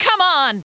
女生说come on 音效_人物音效音效配乐_免费素材下载_提案神器